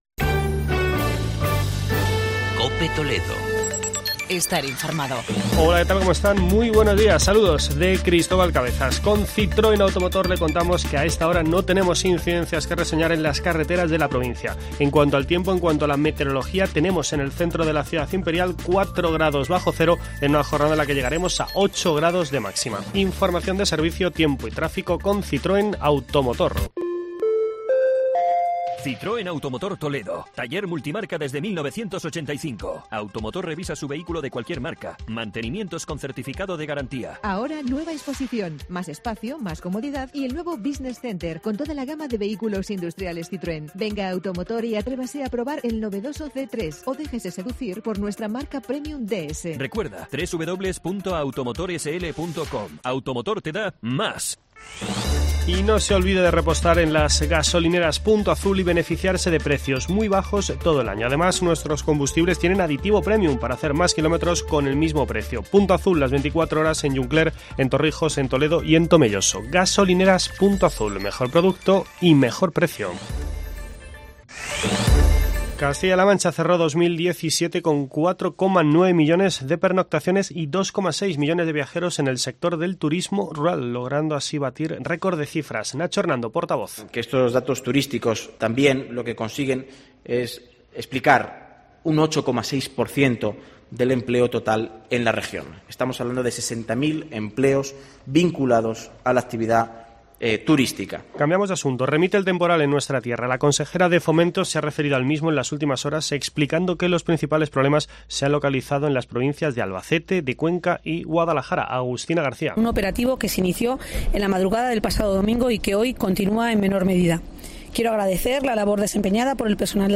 Informativo matinal de la Cadena COPE en la provincia de Toledo.